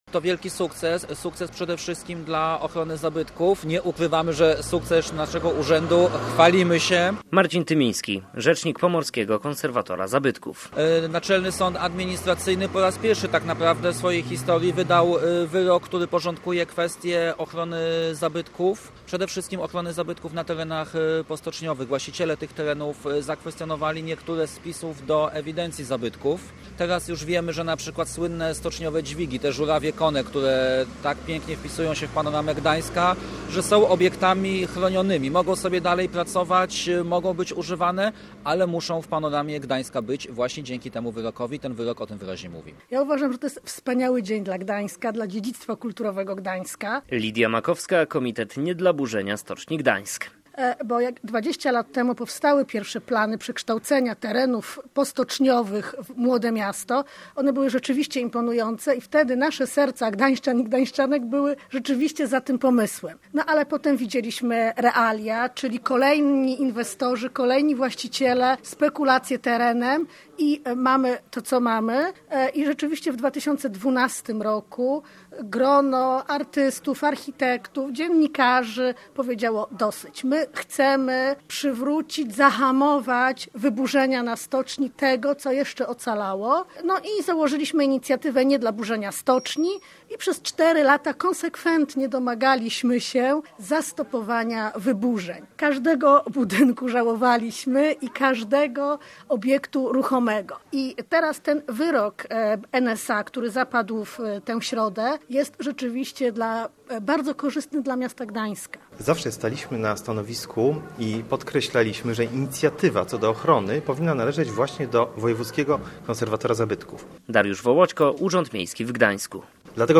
W konserwatorskiej ewidencji jest blisko 250 obiektów, w tym kilkanaście stoczniowych żurawi. O sprawie posłuchać można w reportażu